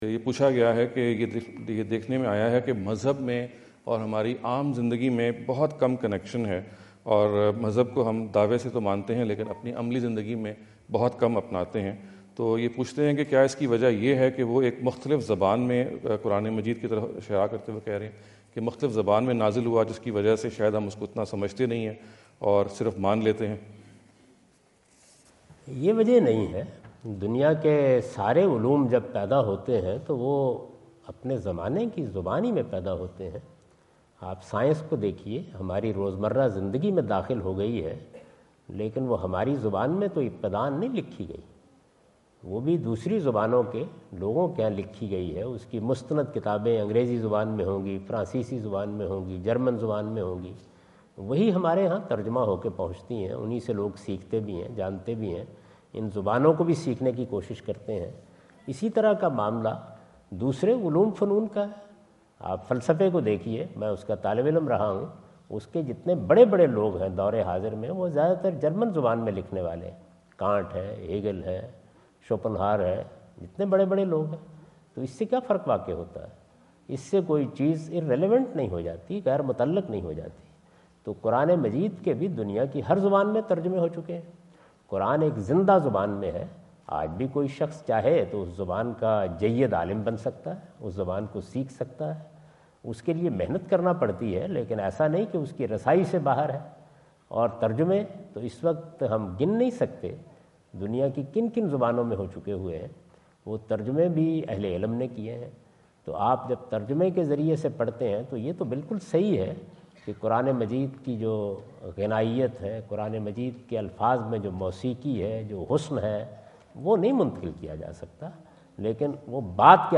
Category: English Subtitled / Questions_Answers /
Javed Ahmad Ghamidi answer the question about "ٰIs Religion Irrelevant in Practical Life?" asked at The University of Houston, Houston Texas on November 05,2017.